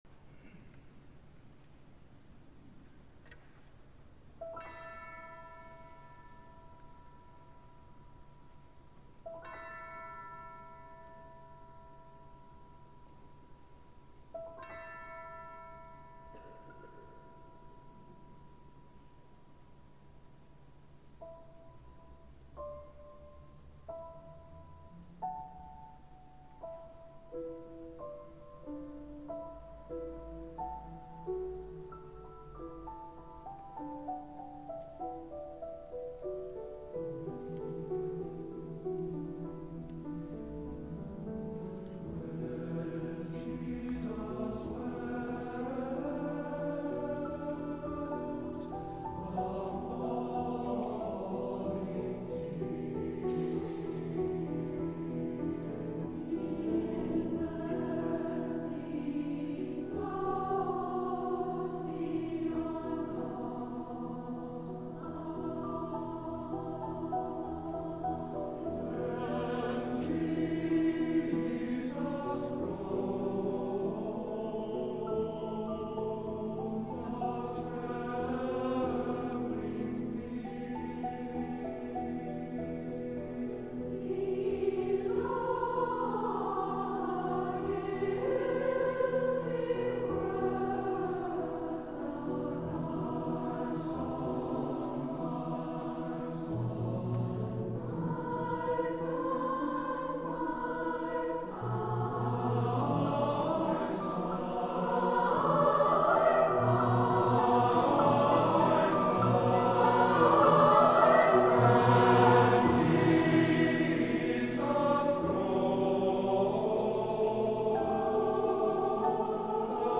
for SATB Chorus, Piano, and Opt. Percussion (2006)